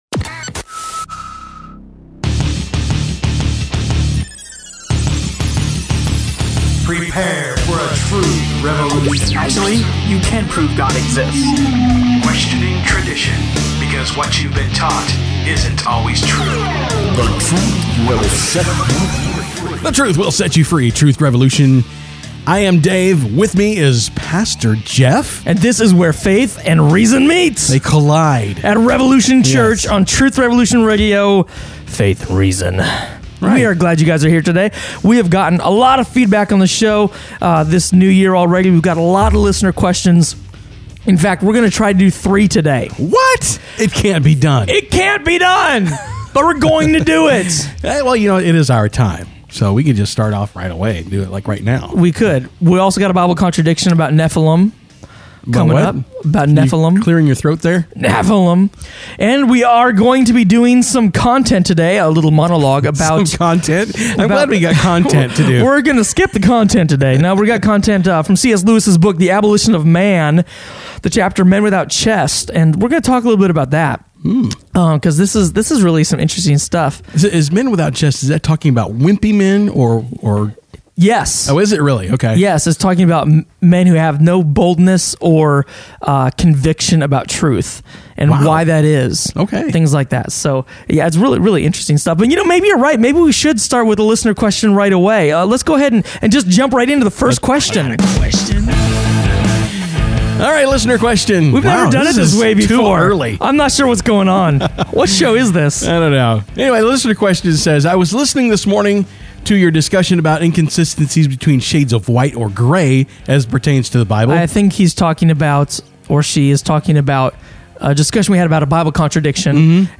Beauty and Morality – Truth Revolution Radio Show